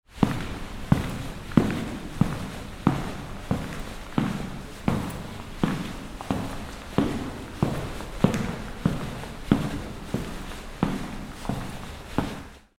Rubber-Soled Footsteps On Hard Surface Sound Effect
A person walks at a moderate pace indoors, creating soft echoes with each step. Their autumn or winter jacket gently rustles as they move. Human sounds.
Rubber-soled-footsteps-on-hard-surface-sound-effect.mp3